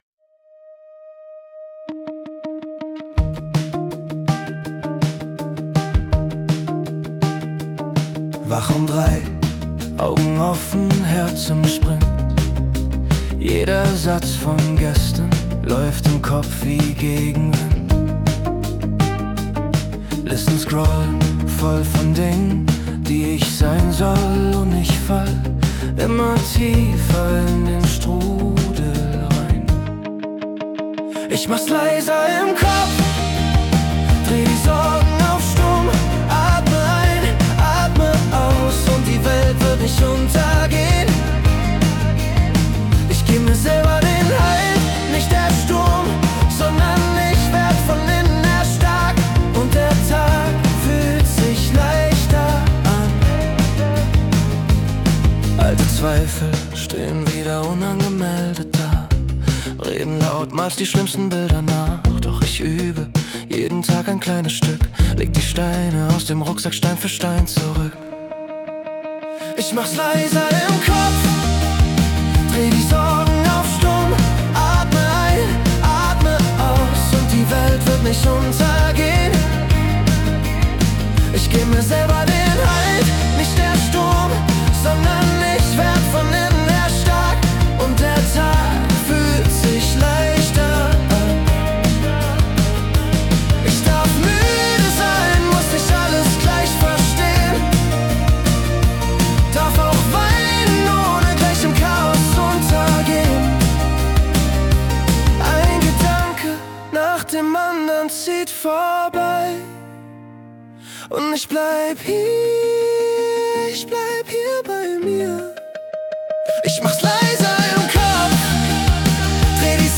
mit männlicher Gesangsstimme